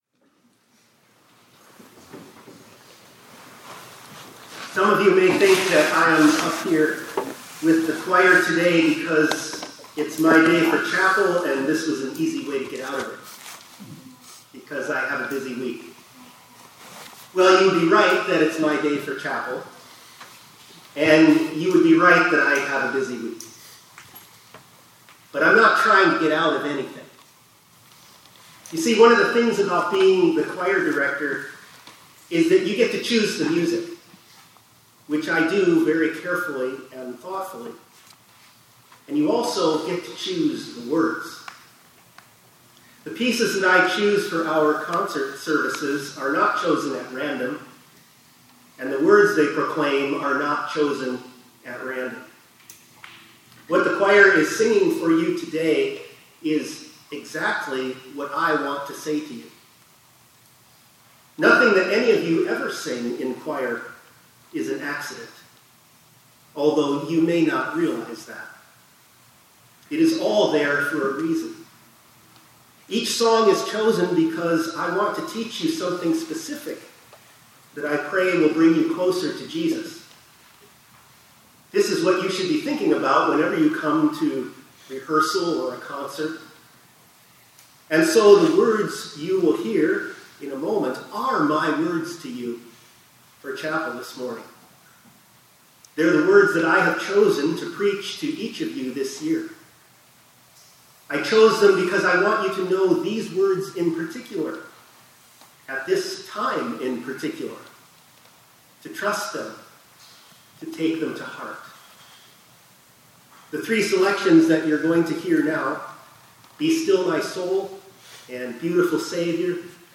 2025-05-12 ILC Chapel — Tour Choir Selections